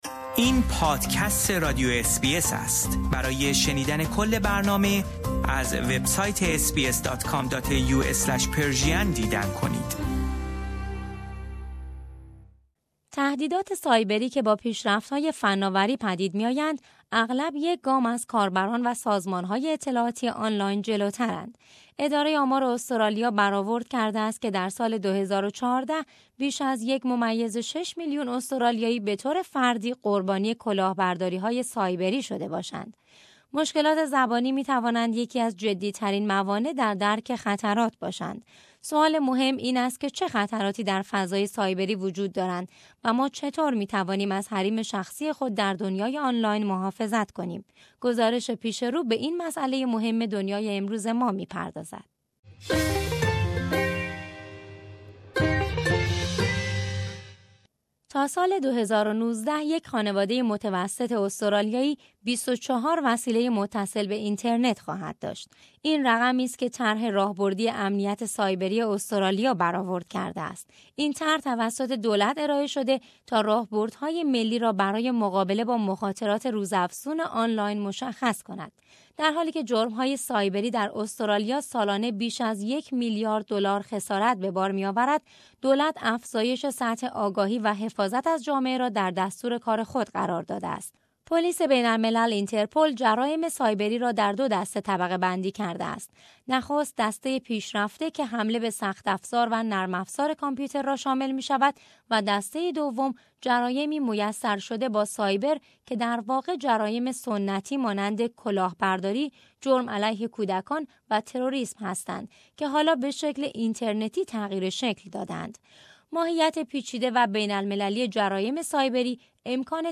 سوال مهم این است که چه خطراتی در فضای سایبری وجود دارند و ما چه طور می‌توانیم از حریم شخصی خود در دنیای آنلاین محافظت کنیم. گزارش پیش رو به این مسألۀ مهم دنیای امروز ما می‌پردازد.